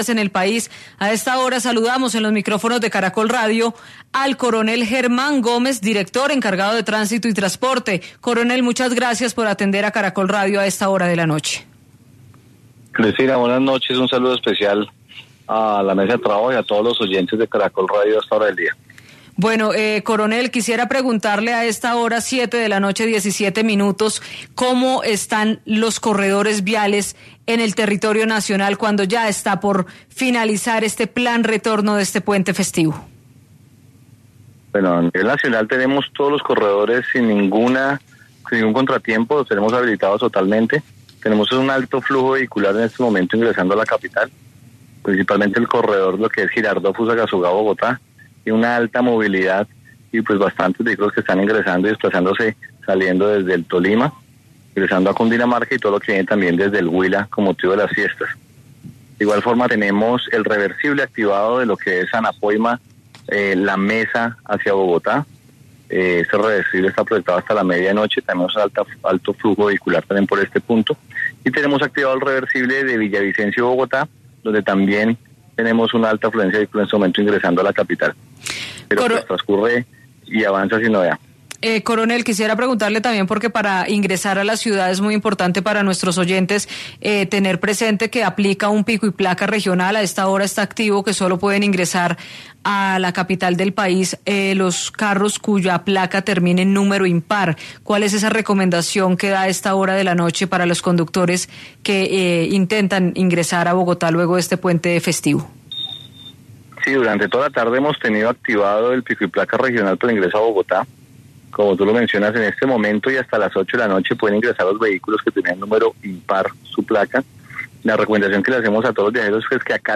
Coronel Germán Gómez, director encargado de la Policía de Tránsito y Transporte.